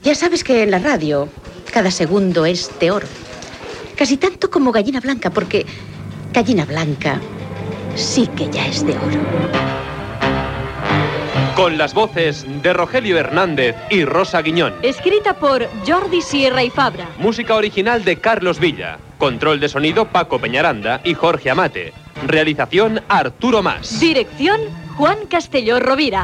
Careta de l'espai "Ya es de oro"
L'espai «Ya es de oro», fet des de Ràdio Barcelona, estava dedicat al cinquantè aniversari de Gallina Blanca.